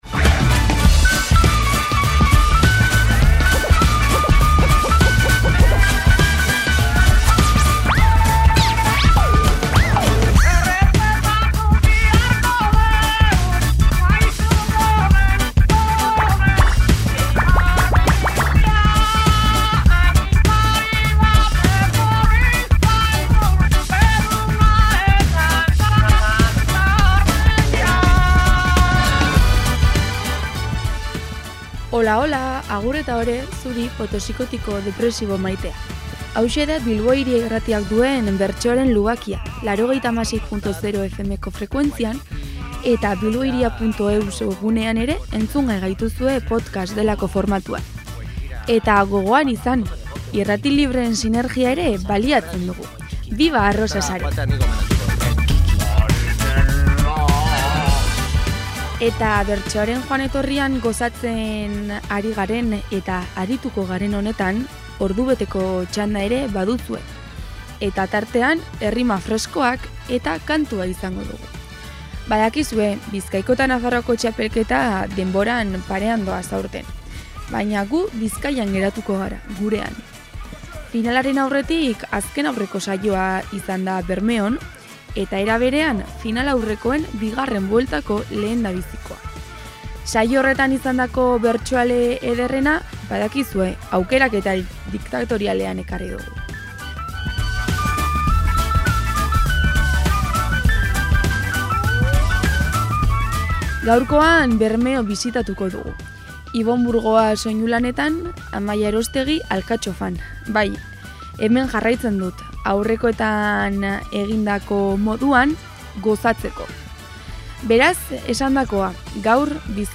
Bizkaiko Bertsolari Txapelketa bukaerara heltzen ari da. Finalaren aurretik, azken-aurreko saioa izan zen Bermeon, eta, era berean, finalaurrekoen bigarren bueltako lehendabizikoa. Saio horretan izandako bertsoen aukeraketa diktatoriala duzue gaurkoan entzungai.